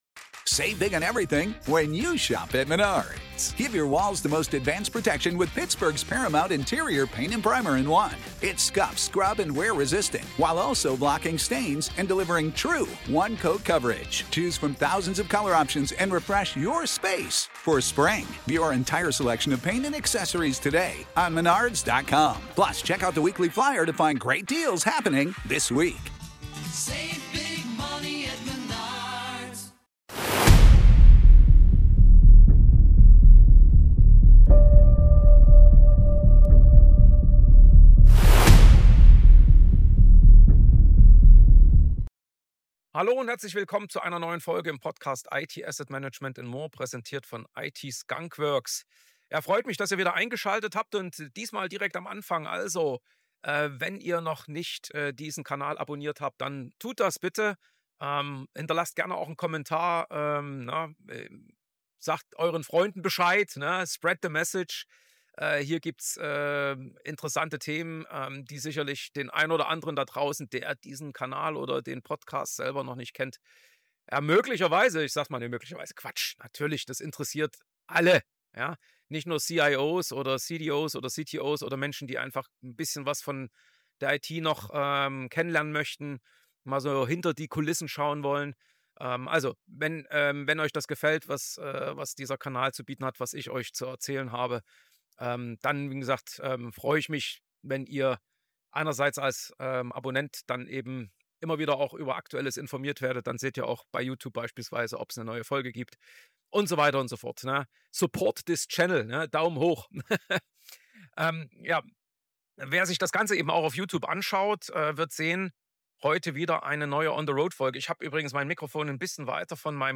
In dieser On-the-Road-Folge geht es wieder um Innovation – genauer gesagt um einen Punkt, der in Veränderungsprojekten oft unterschätzt wird: Zeit.